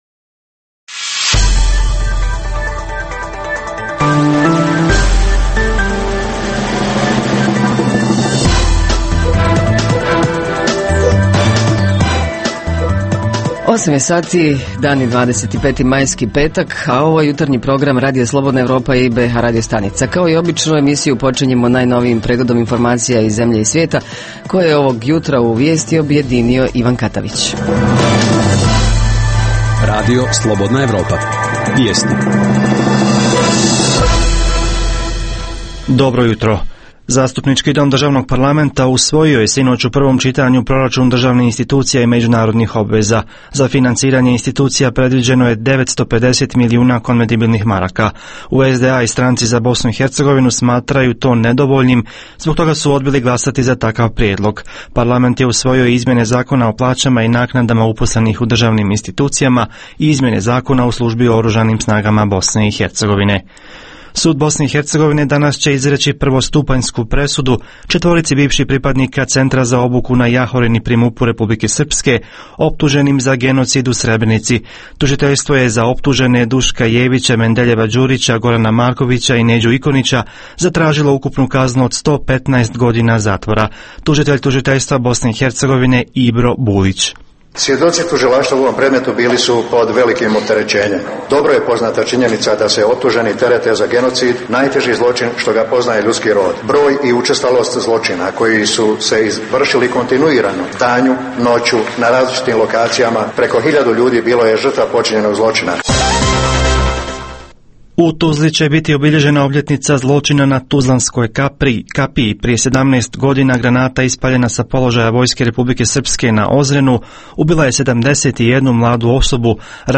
Jutarnji program za BiH koji se emituje uživo. Ovog petka reporteri iz cijele BiH javljaju o najaktuelnijim događajima i zanimljivostima iz njihovih sredina.
Redovni sadržaji jutarnjeg programa za BiH su i vijesti i muzika.